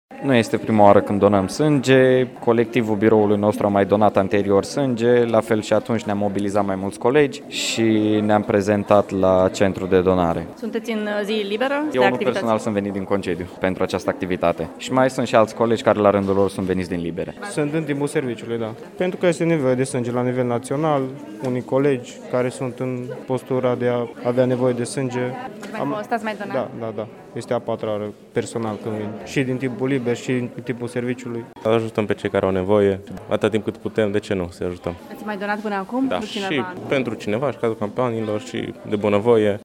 Polițiștii mureșeni care au donat astăzi sânge nu sunt la prima activitate de acest fel iar unii au venit din concediu pentru asta: